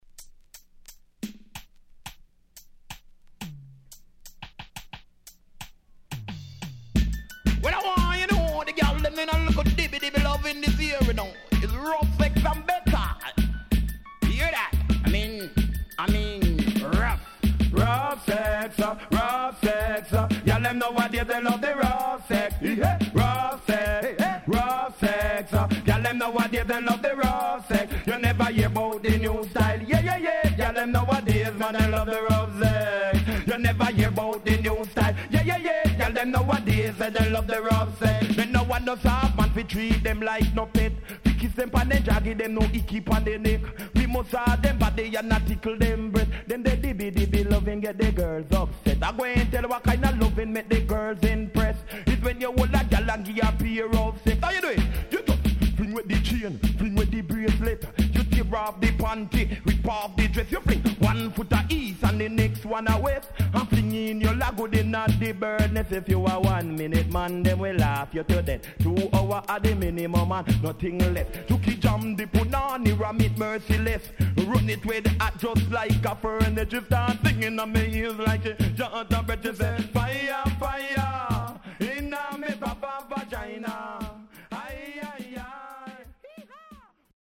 HOME > Back Order [DANCEHALL DISCO45]